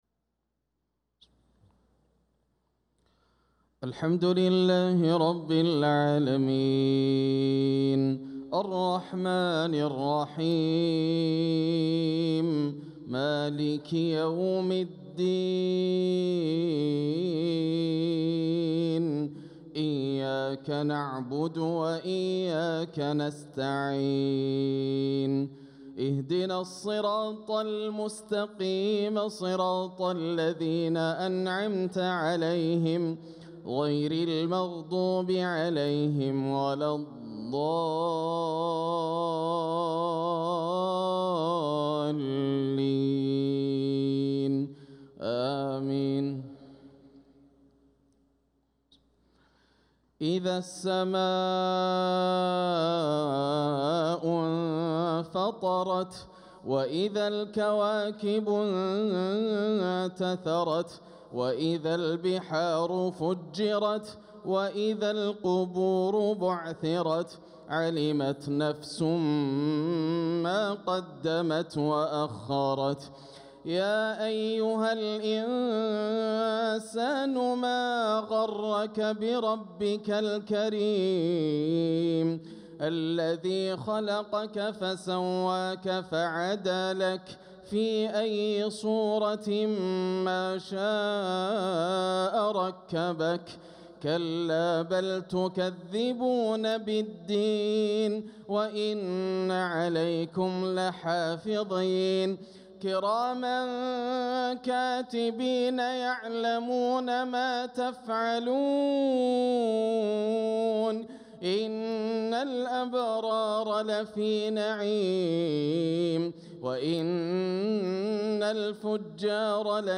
صلاة العشاء للقارئ ياسر الدوسري 28 صفر 1446 هـ
تِلَاوَات الْحَرَمَيْن .